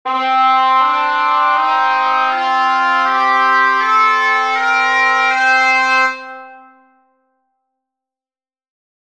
For the curious, I used Musescore2 to create these scale samples, due to it’s easy-to-apply tuning adjustments on notes, with the bagpipe sound specifically because it did not have vibrato.
modedorian.mp3